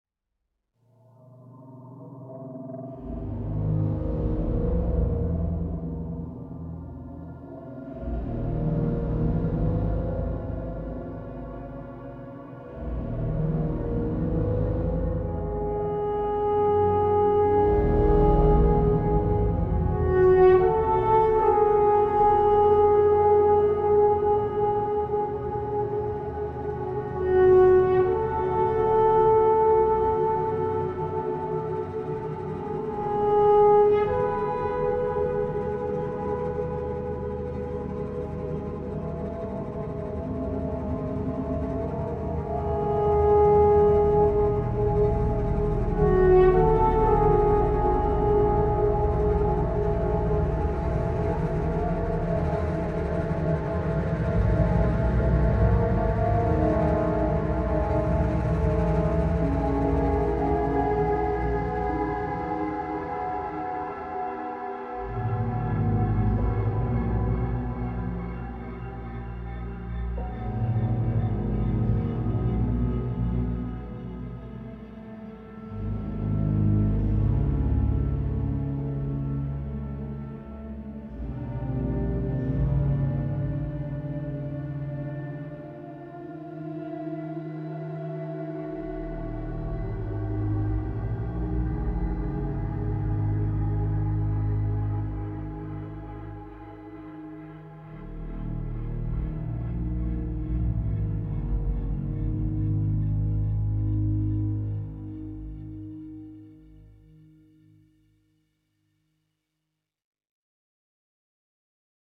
Ambience 3 - The Chamber.wav